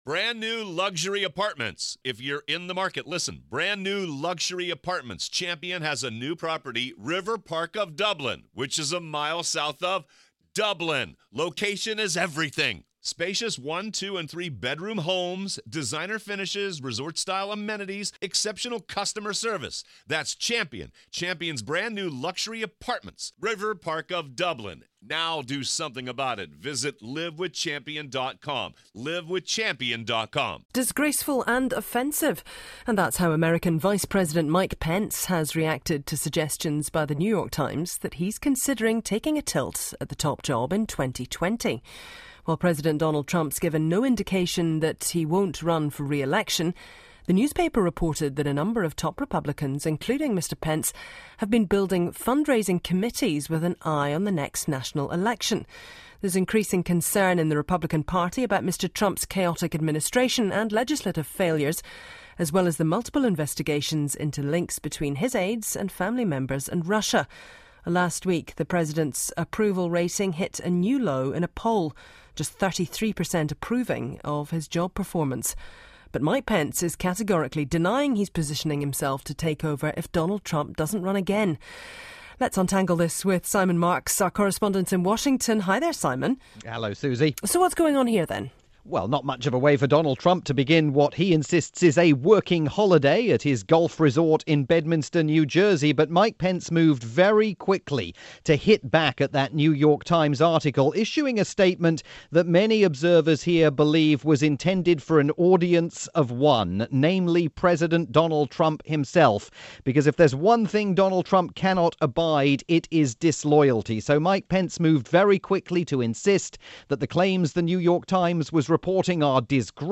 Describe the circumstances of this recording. Via Radio New Zealand's "Morning Report"